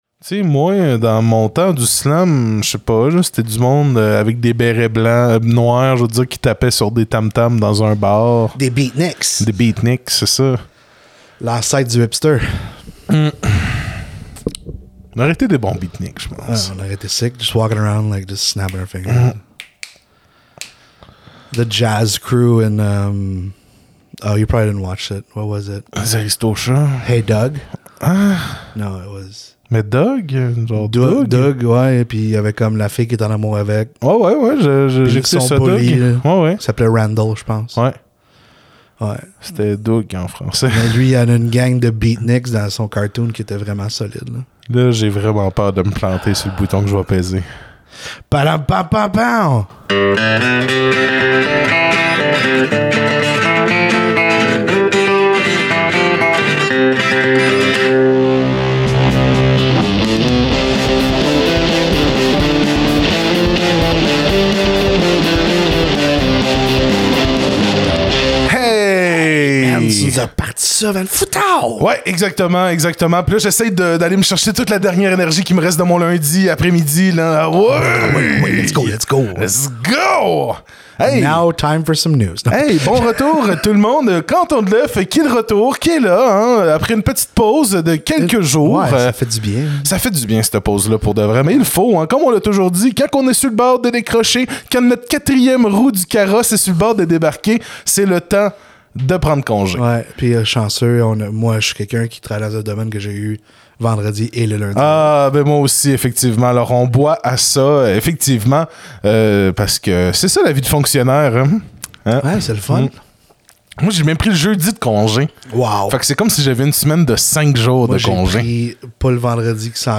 Cantons de Left, c’est un podcast bilingue qui veut vous en apprendre davantage sur la politique, le syndicalisme et les luttes ouvrières. Nous apportons une vision citoyenne et progressive aux enjeux qui touchent notre monde moderne. Animé par deux vieux punks de Sherbrooke dans les Cantons-de-l'Est, le podcast vous offrira une mise à jour hebdomadaire sur ce qui se passe dans les milieux de travail du Québec, du Canada et du monde!/Cantons de Left is a bilingual podcast that aims to inform you